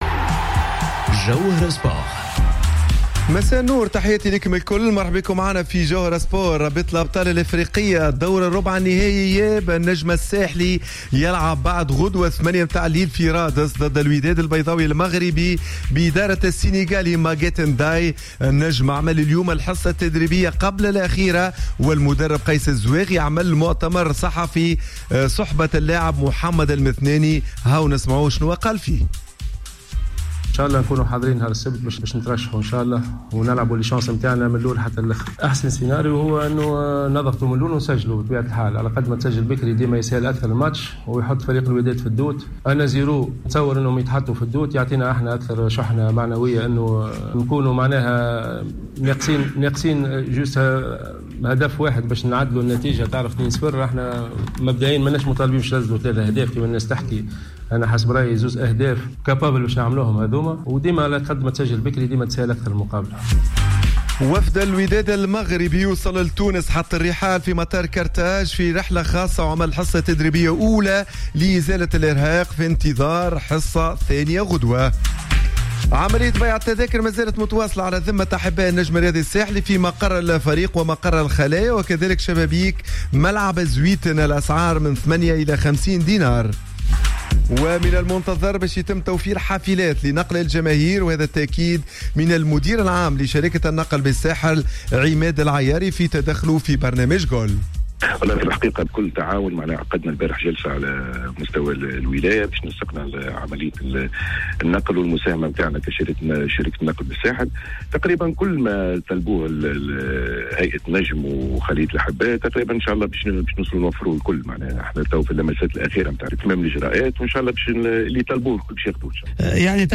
مؤتمر صحفي